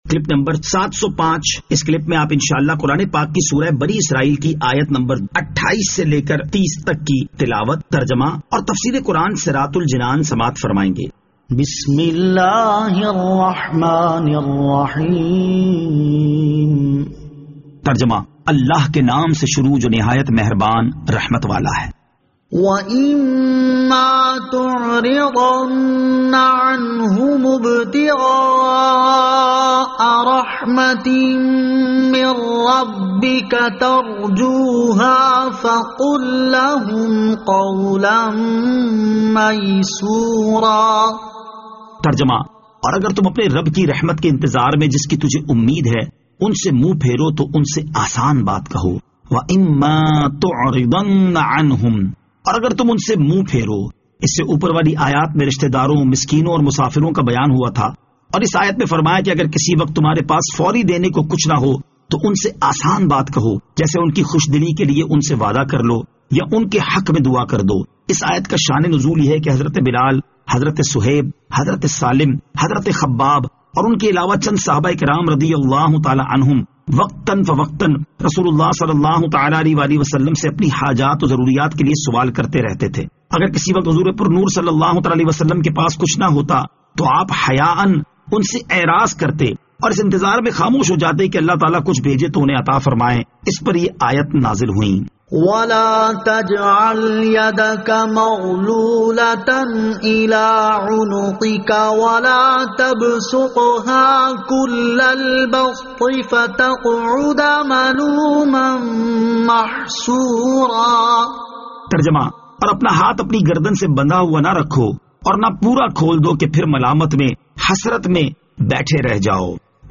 Surah Al-Isra Ayat 28 To 30 Tilawat , Tarjama , Tafseer